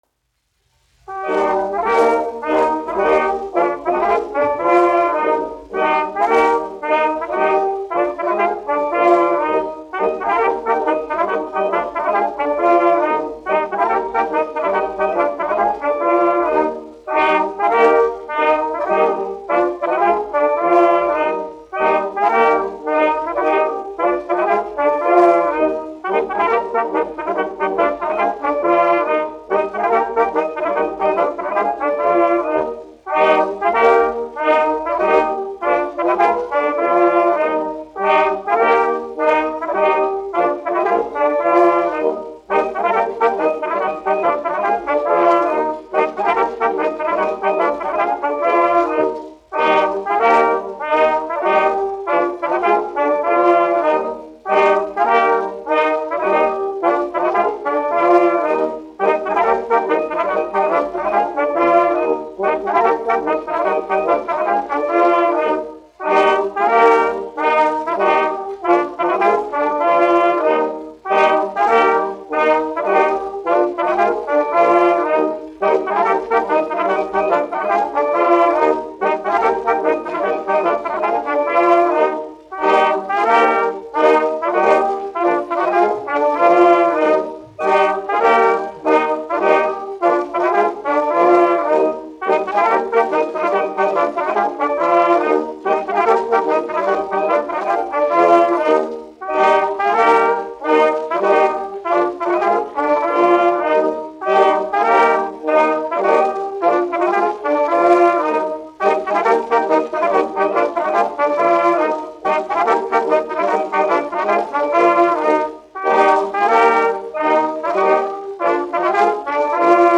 1 skpl. : analogs, 78 apgr/min, mono ; 25 cm
Latviešu tautas dejas
Pūtēju orķestra mūzika
Skaņuplate